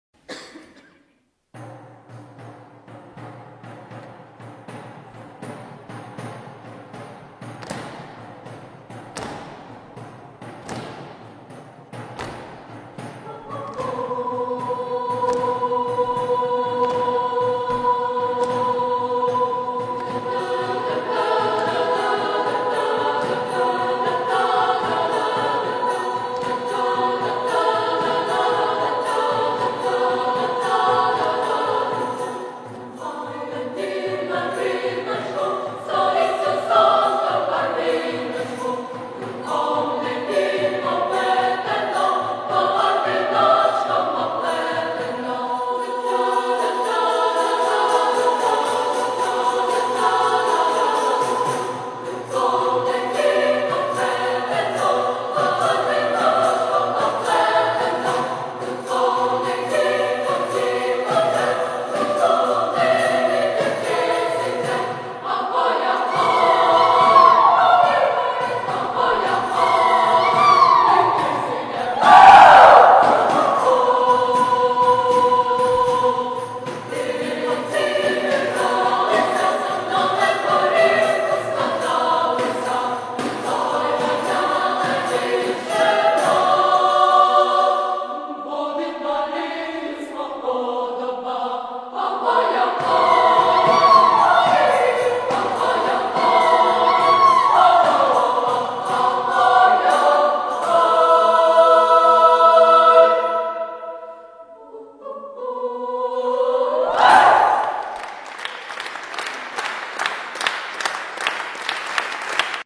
SSA choir and percussion
The song ends with merry laughter.